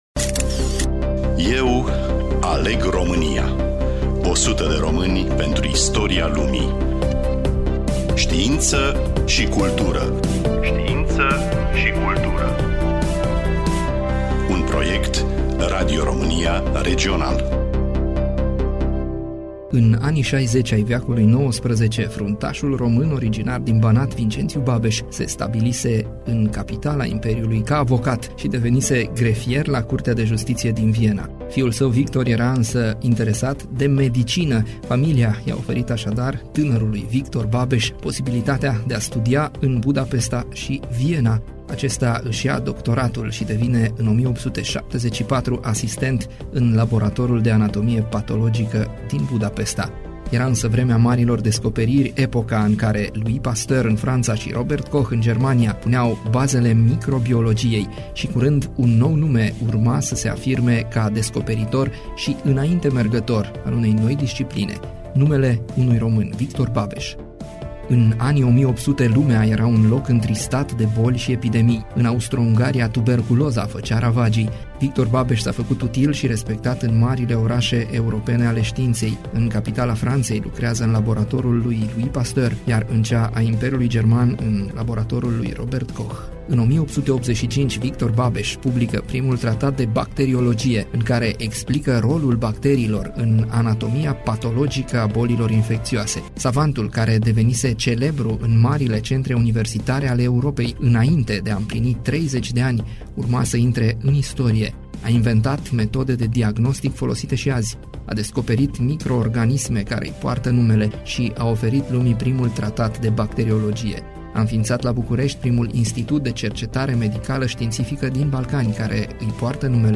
Voiceover